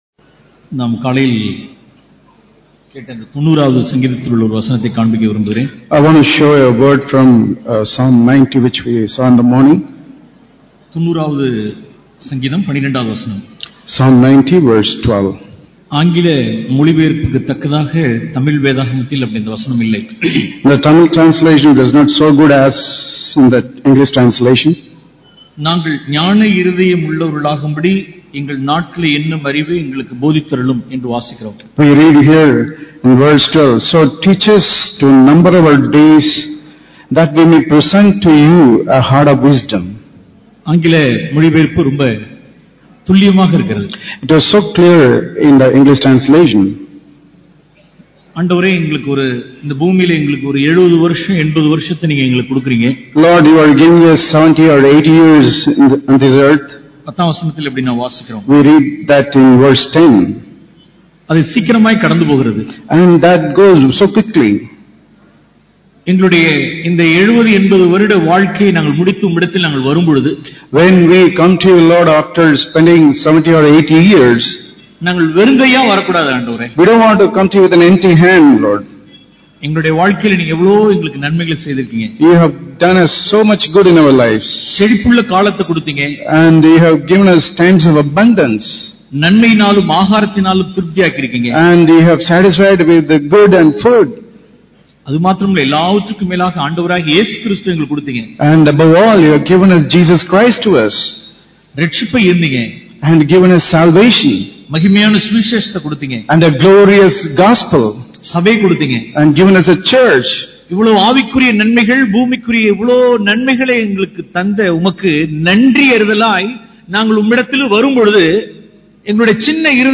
Count Our Days to Present a Heart of Wisdom Tuticorin Conference - 2017: Growing in Heavenly Wisdom